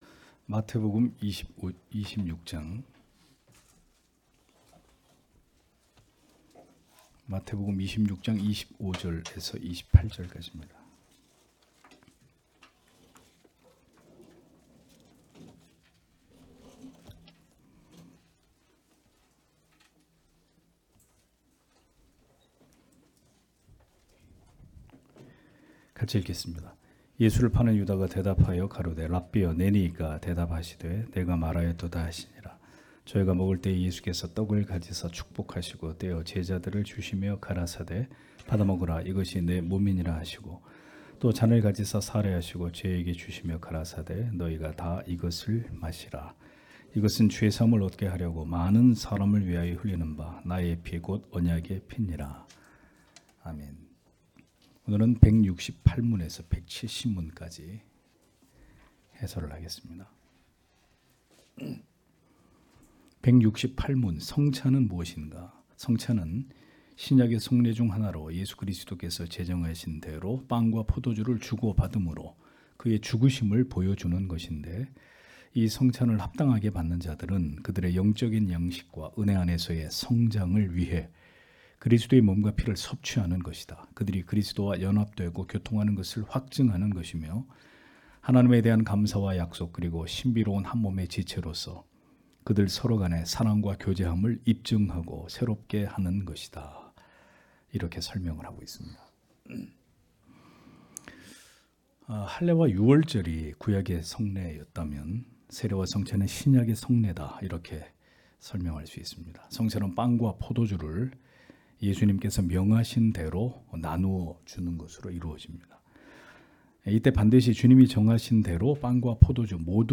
주일오후예배 - [웨스트민스터 대요리문답 해설 168-170] 168문) -170문) (마태복음 26장 25-28절)